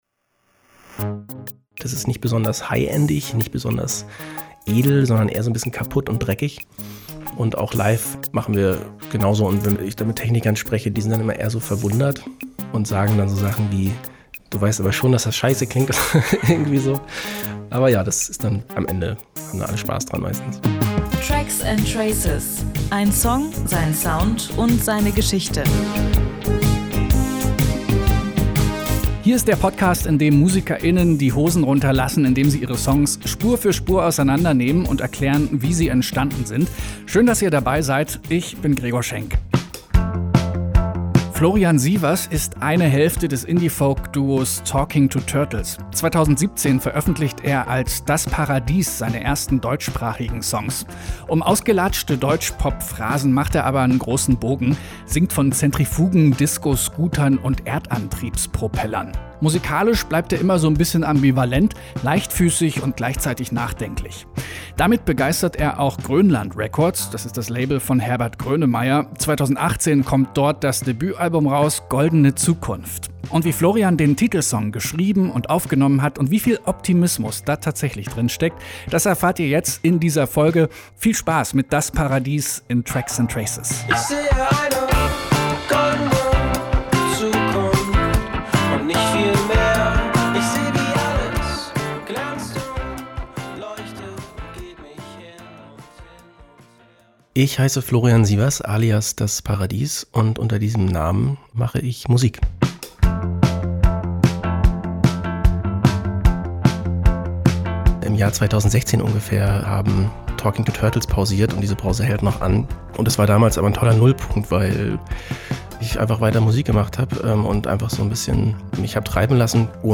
Gebettet auf federleichter Popmusik, der man sich allzu gerne hergibt.